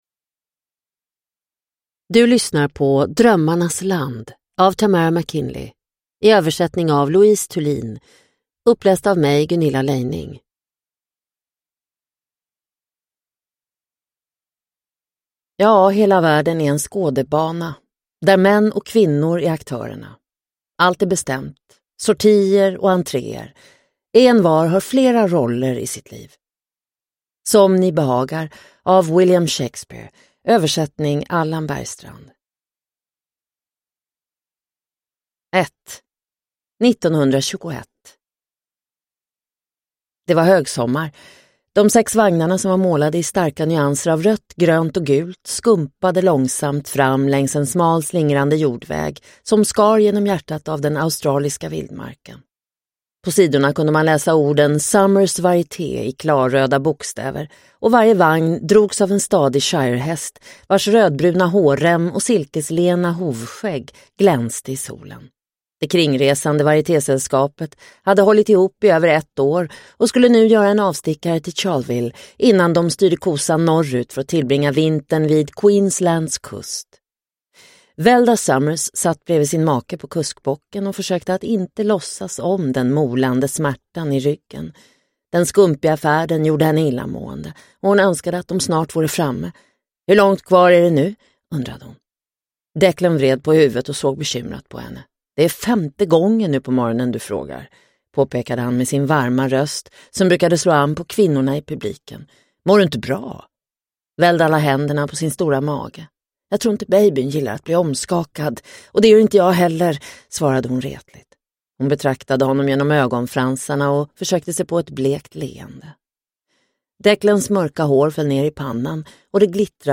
Drömmarnas land – Ljudbok – Laddas ner